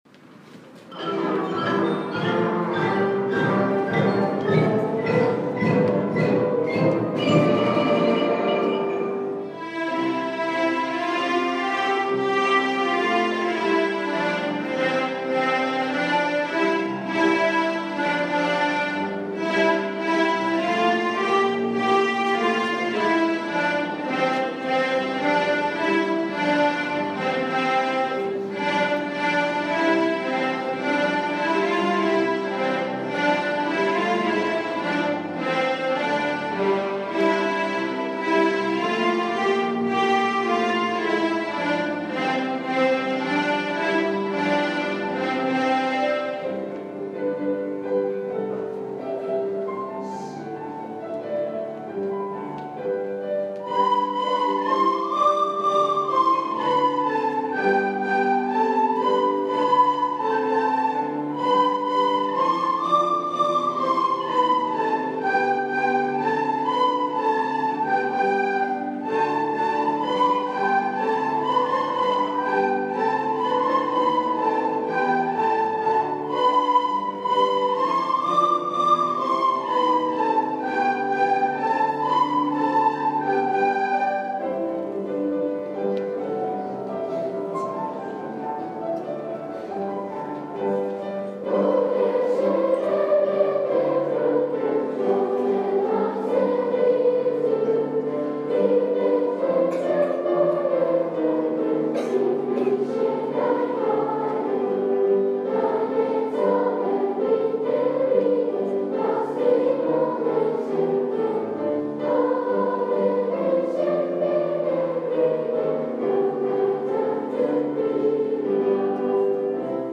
音楽集会
今日の集会では、全校でベートーベンの交響曲第九番 「歓びの歌」を合奏・合唱しました。 低学年は鍵盤ハーモニカ、中学年はリコーダー、高学年は歌をドイツ語で歌い、音楽クラブが金管楽器や、打楽器などでオープニングとエンディングを飾りました。 体育館にはきれいで迫力あるハーモニーが生まれましたので、皆様も是非お聞きください。